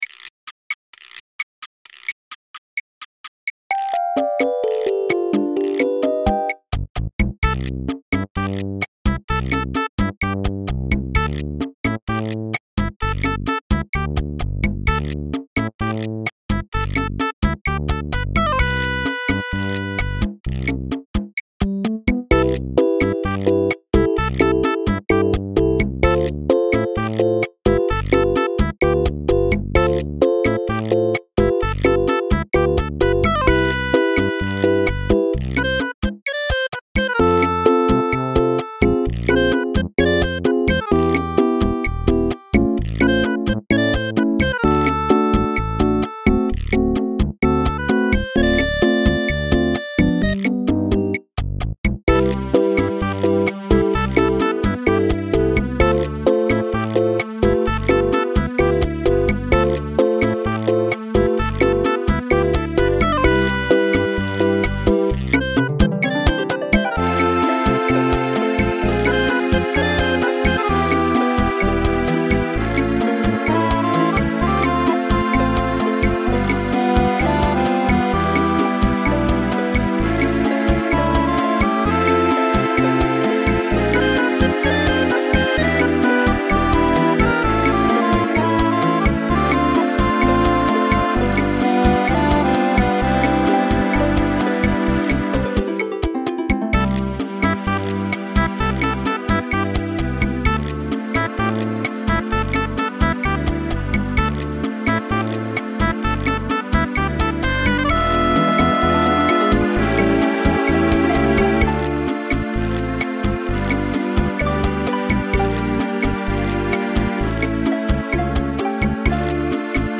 A cute bossa nova elevator music midi track.